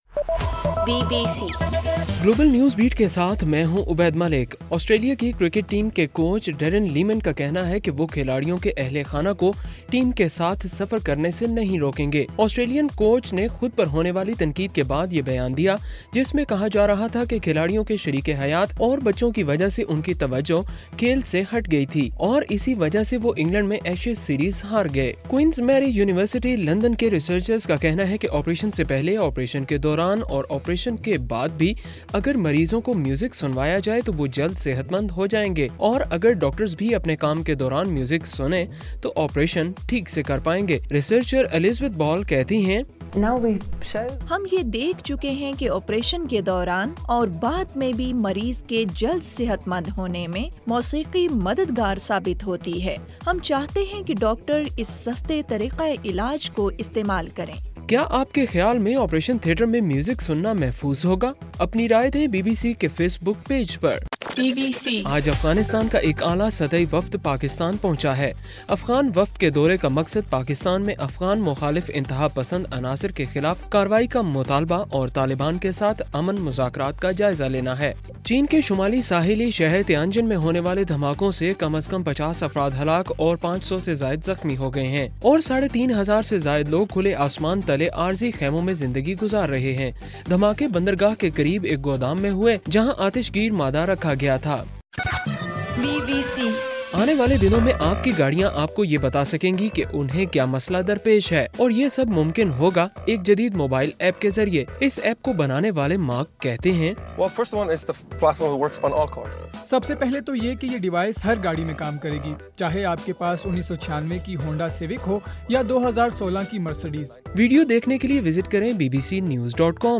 اگست 13: رات 11 بجے کا گلوبل نیوز بیٹ بُلیٹن